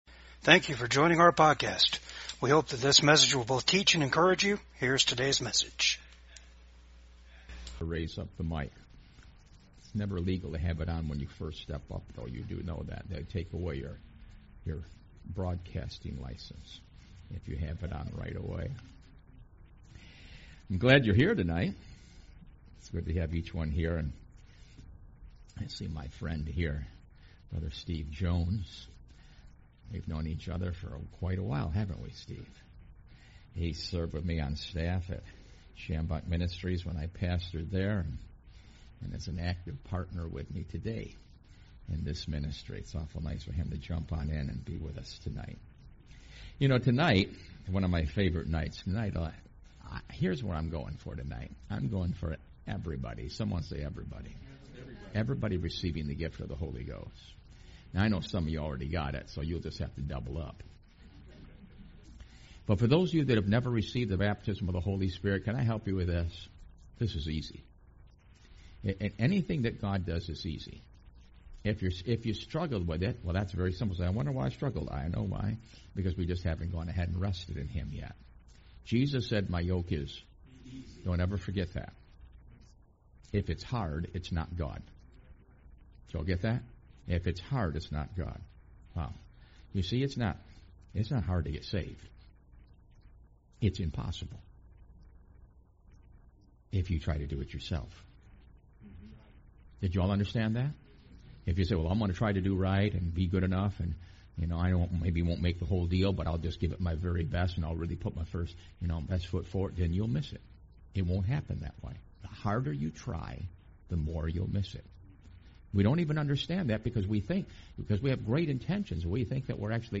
Service Type: REFRESH SERVICE